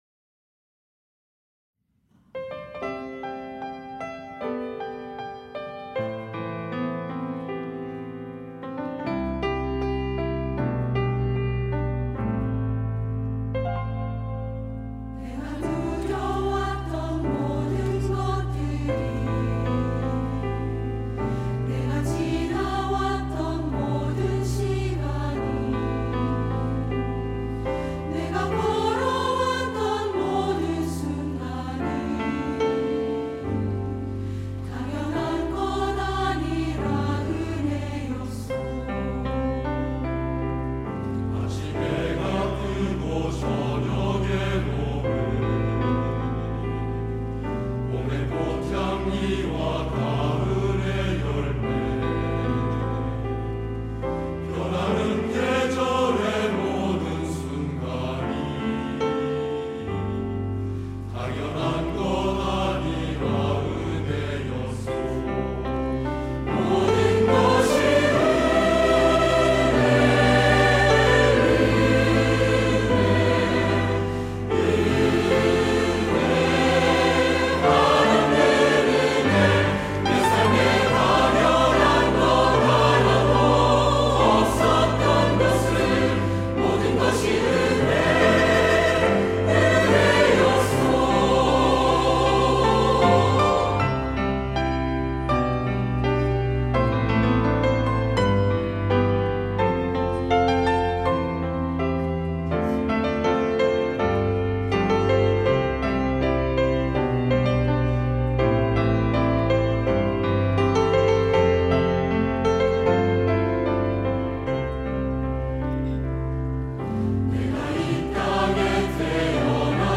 할렐루야(주일2부) - 은혜
찬양대